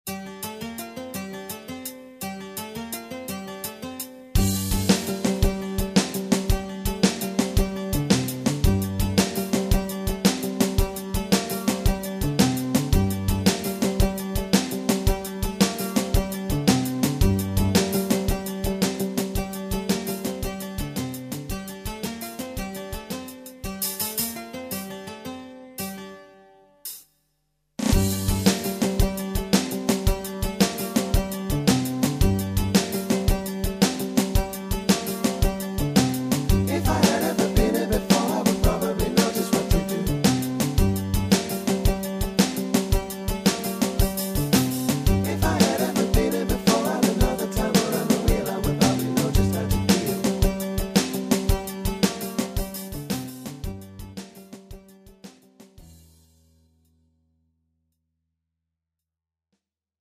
(팝송) MR 반주입니다